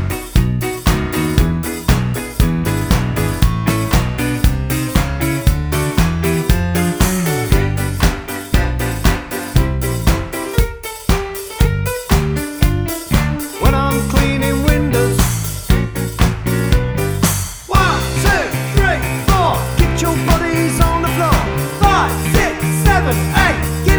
no Backing Vocals Rock 'n' Roll 4:22 Buy £1.50